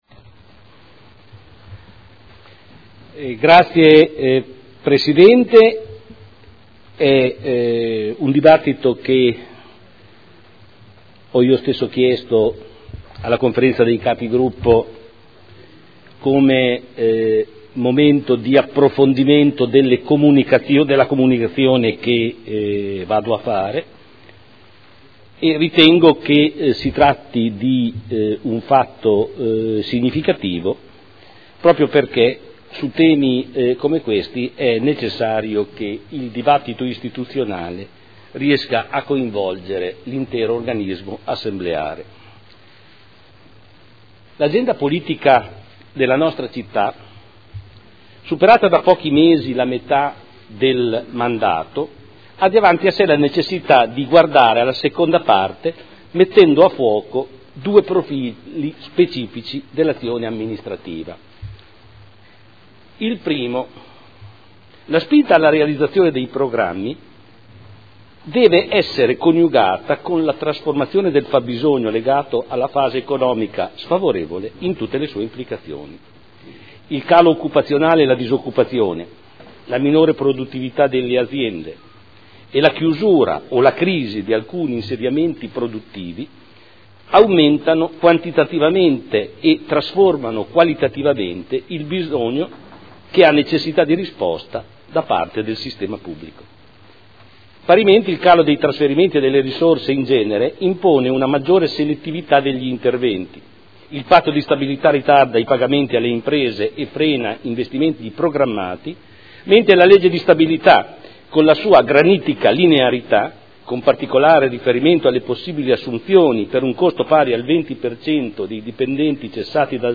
Seduta del 23/04/2012. Comunicazione del Sindaco sulla composizione della Giunta.